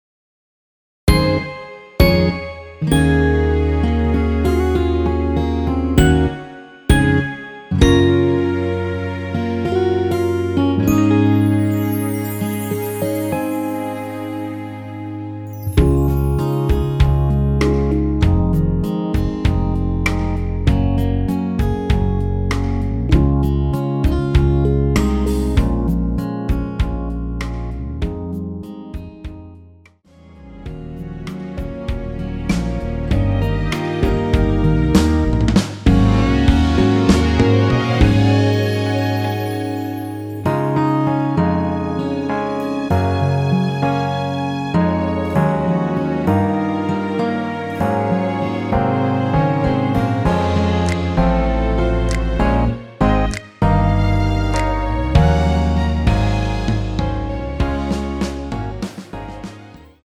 원키에서(-1)내린 1절후 후렴으로 진행되게 편곡된 MR입니다.
Db
앞부분30초, 뒷부분30초씩 편집해서 올려 드리고 있습니다.
중간에 음이 끈어지고 다시 나오는 이유는